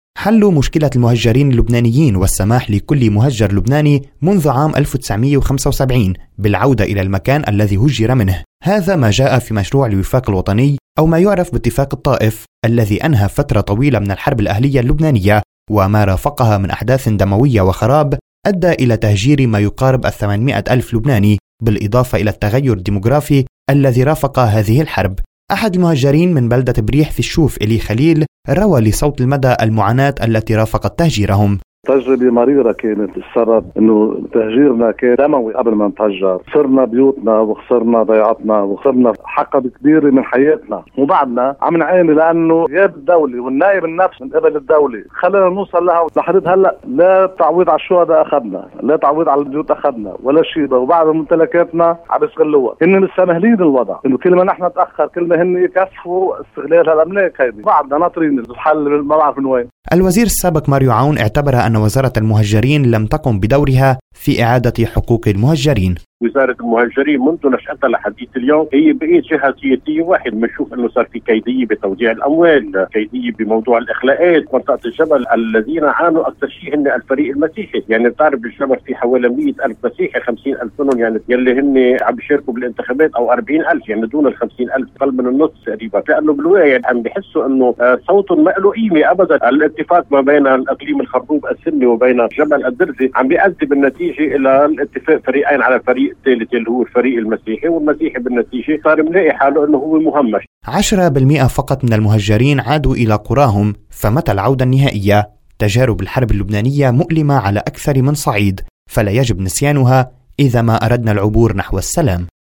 تاريخ وعبر نحو السلام"تقرير